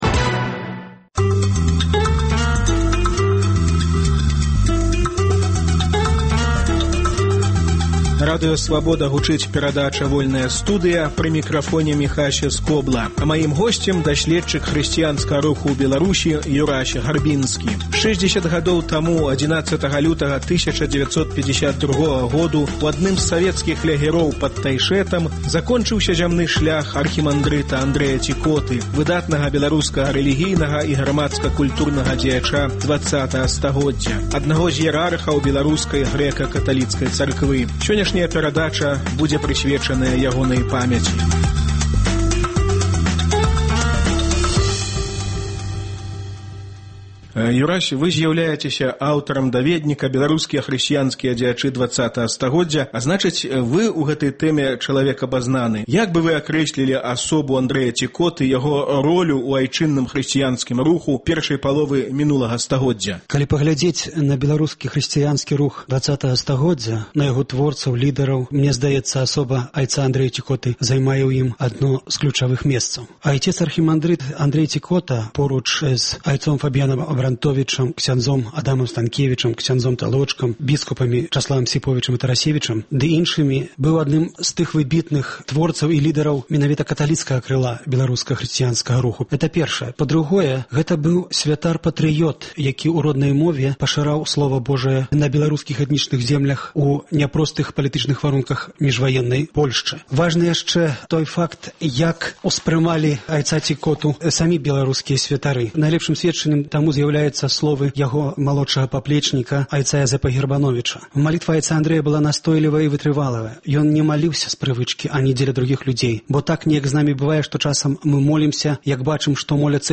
Да 60-х угодкаў ягонай пакутніцкай сьмерці – гутарка з дасьледчыкам хрысьціянскага руху